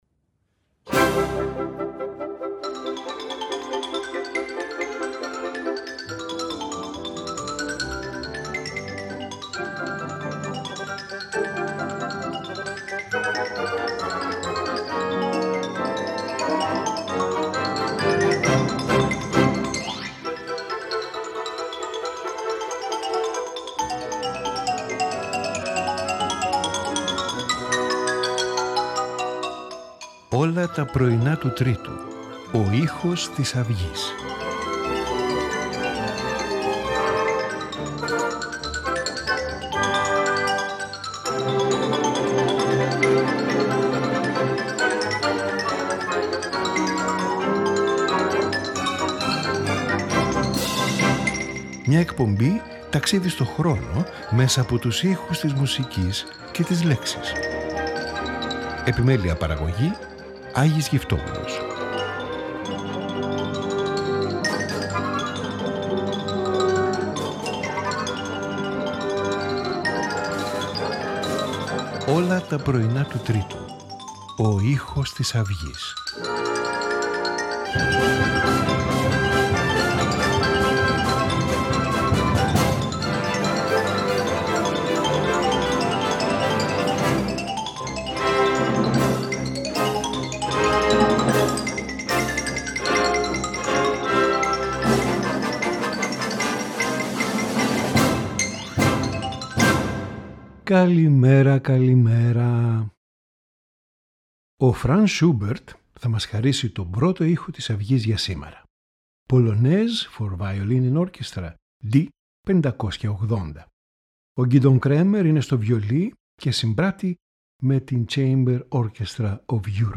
Polonaise for Violin and Orchestra
Piano Concerto in A minor